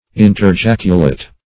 Search Result for " interjaculate" : The Collaborative International Dictionary of English v.0.48: Interjaculate \In`ter*jac"u*late\, v. t. To ejaculate parenthetically.